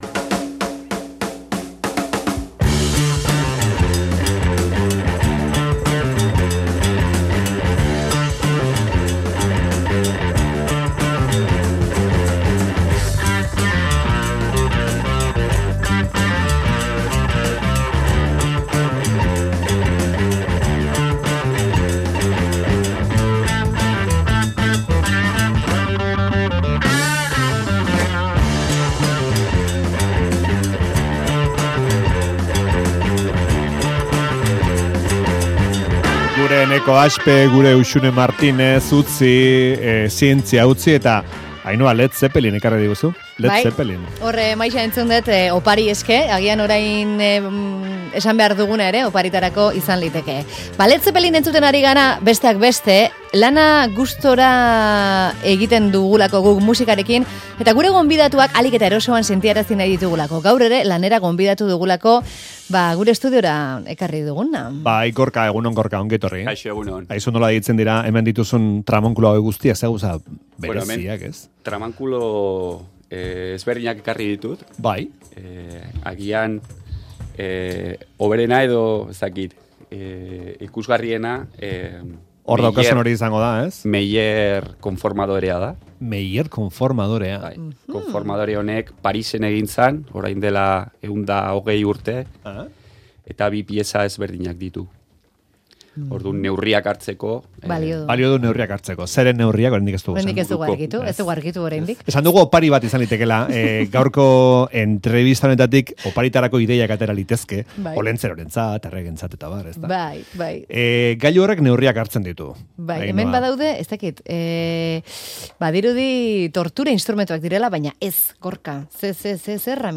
Donostian du berez, baina bi zakuto hartuta estudioan muntatu dugu tailer txikia.